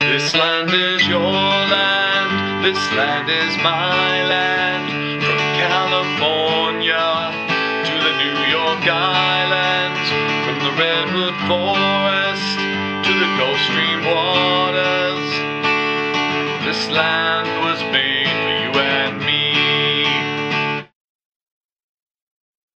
bright-ringtone_14274.mp3